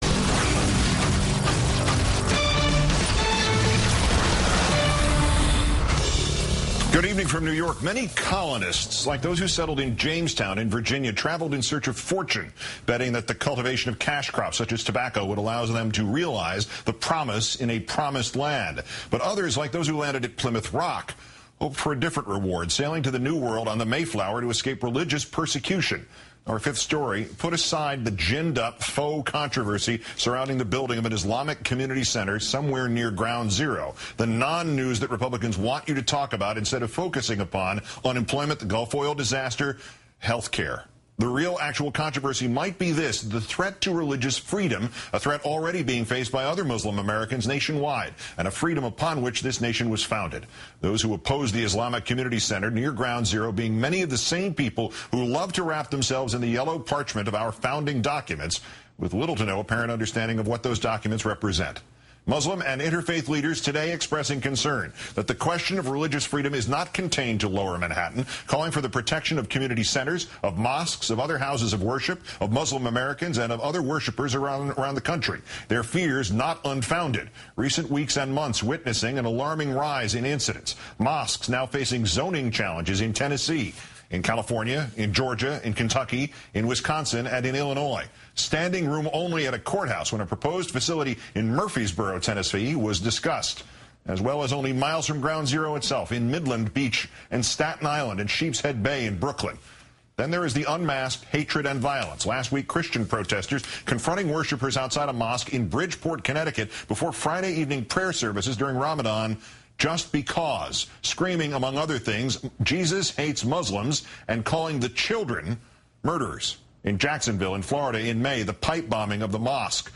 CAIR Rep Talks to Keith Olbermann About Growing Anti-Islam Hysteria